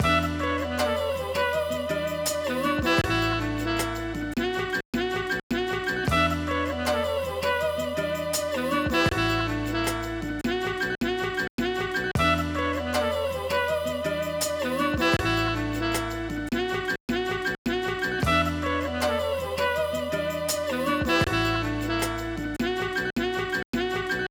Verse Jazz Sample.wav